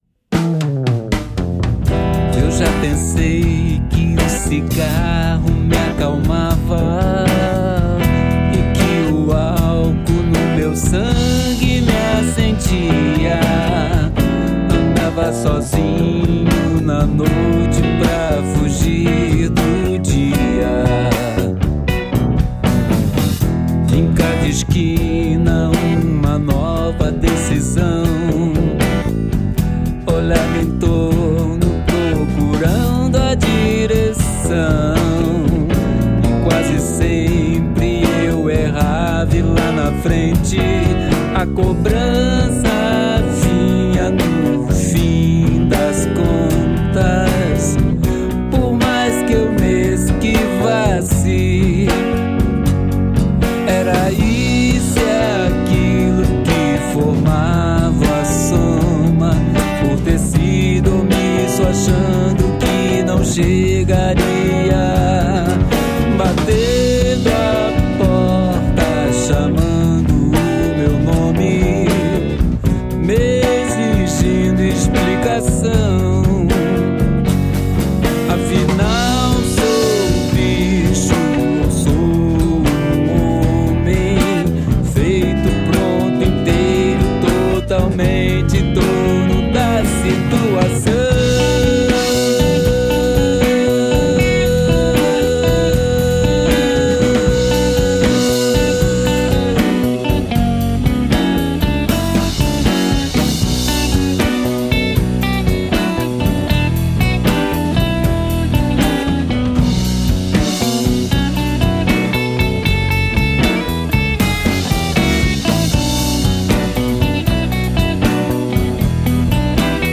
EstiloBlues